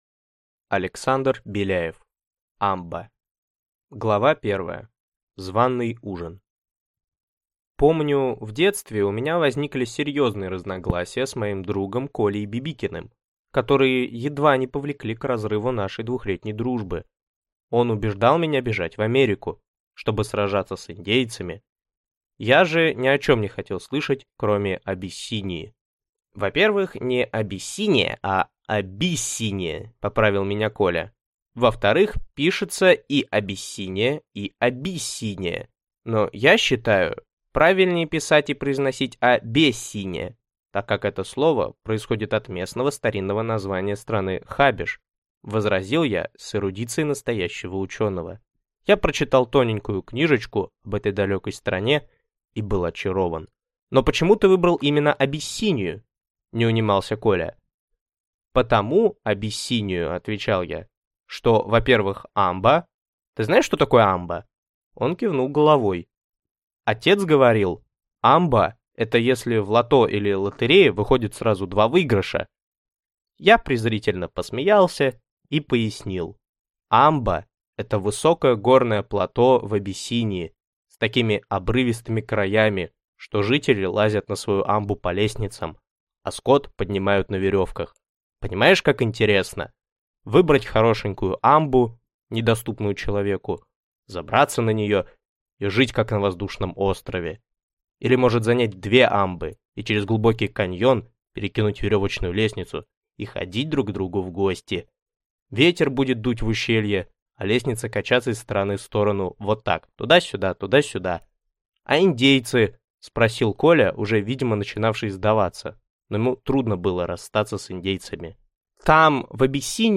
Аудиокнига Амба | Библиотека аудиокниг